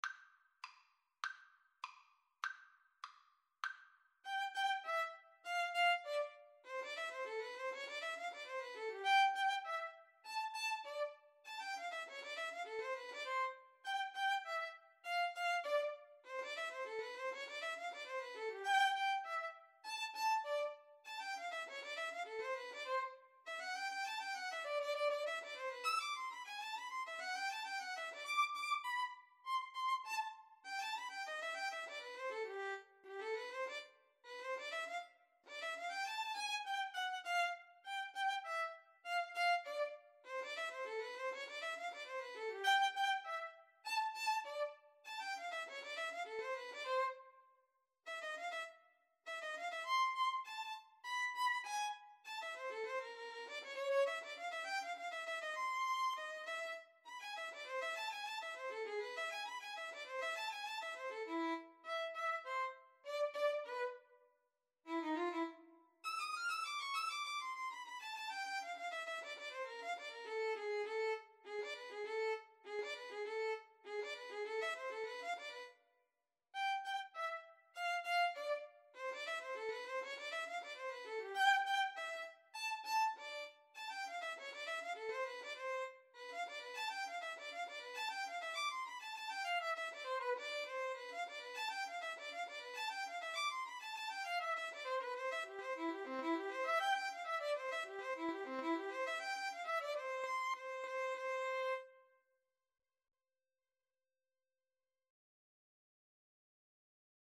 2/4 (View more 2/4 Music)
Violin Duet  (View more Advanced Violin Duet Music)
Classical (View more Classical Violin Duet Music)